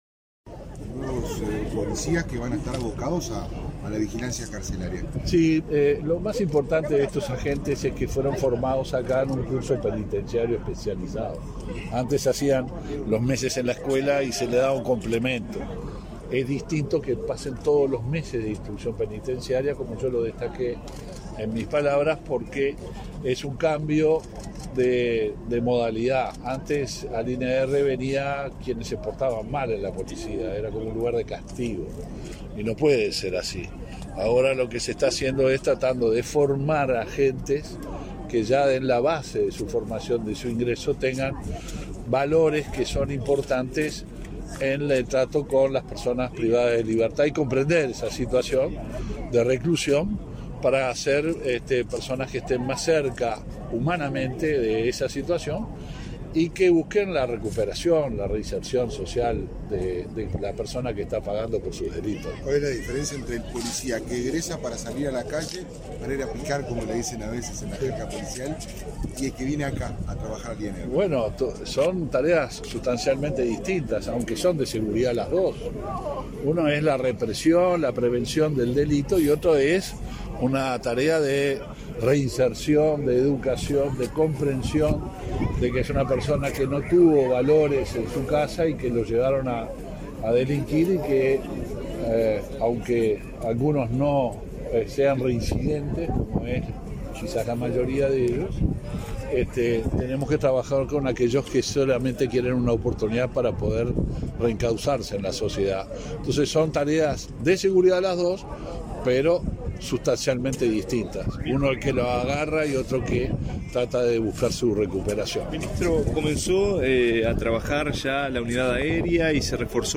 Declaraciones a la prensa del ministro del Interior, Luis Alberto Heber
Tras participar, este 21 de julio, en la ceremonia de egreso de los primeros 24 policías capacitados en el Centro de Formación Penitenciaria,